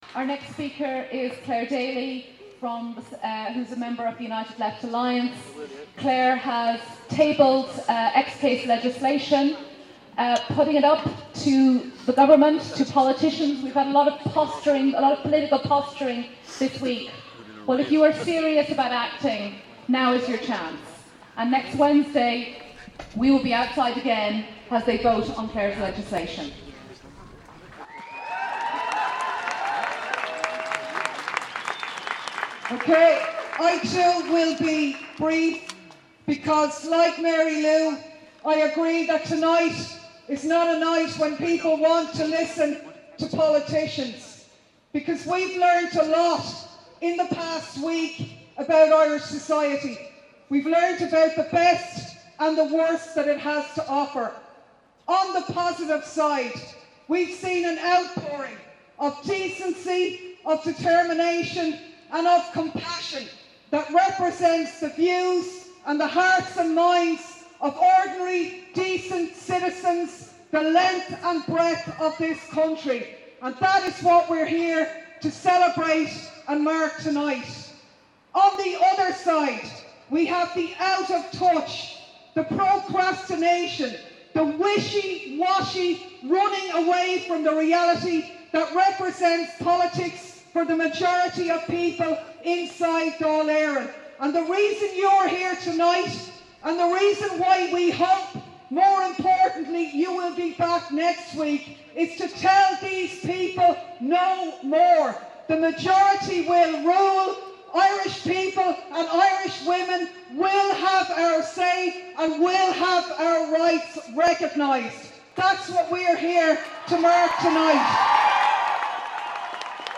Clare Daly TD - Never Again - Pro Choice Demo 21/11/2012